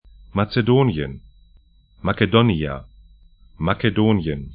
Pronunciation
Makedonien   Matse'do:nĭən